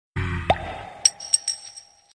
bottle-pop-open_24644.mp3